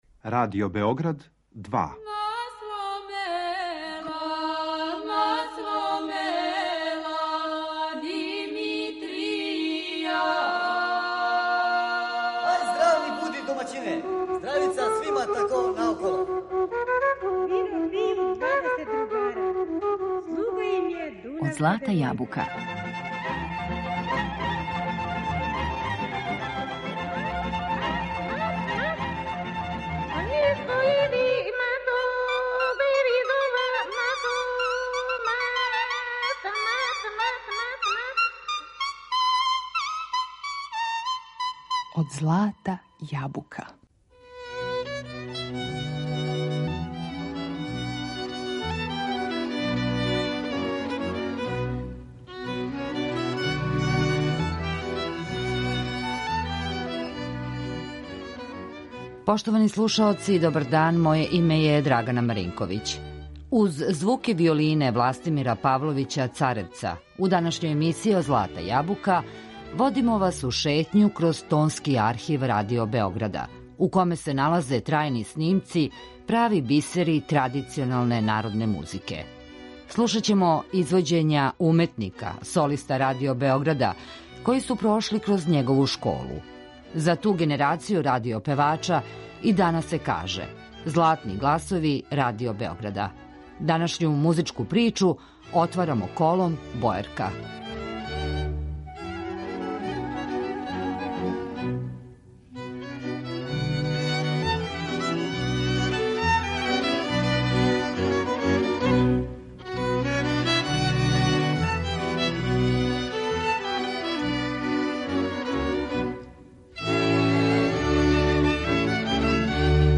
У данашњој емисији Од злата јабука водимо вас у шетњу кроз Тонски архив Радио Београда, где се налазе трајни снимци ‒ прави бисери традиционалне народне музике.